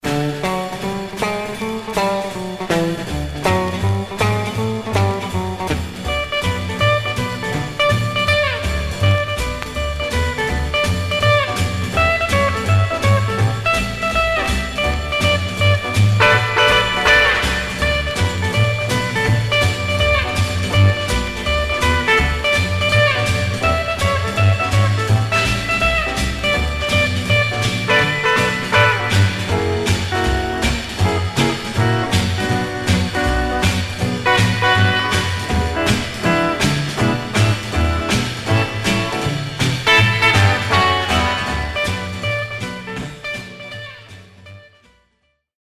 Mono
R & R Instrumental